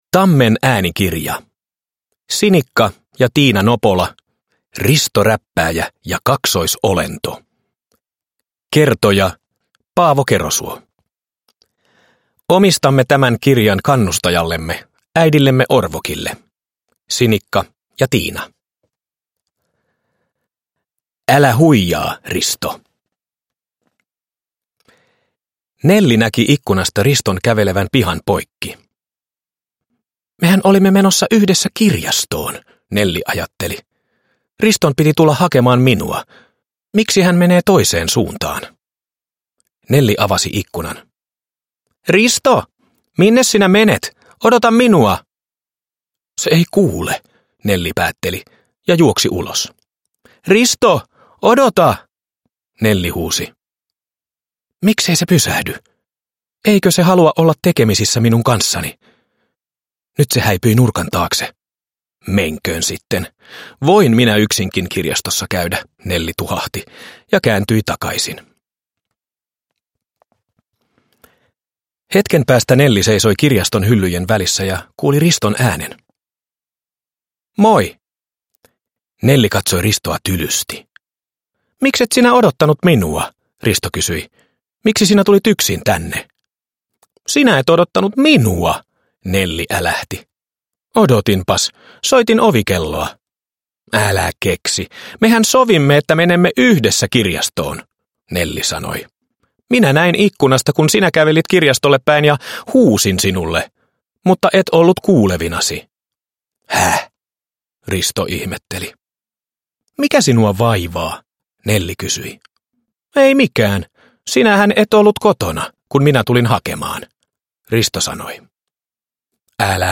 Risto Räppääjä ja kaksoisolento – Ljudbok